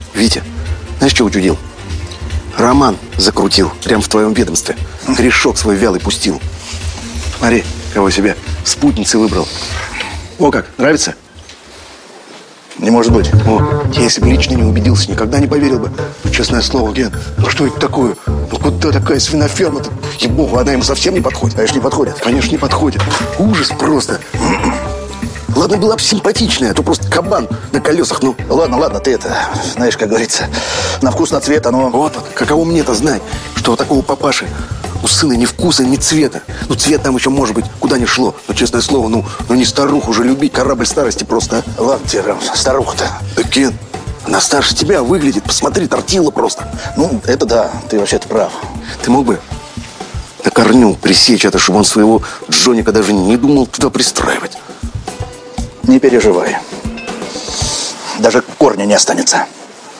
• Качество: 128, Stereo
из сериалов
диалог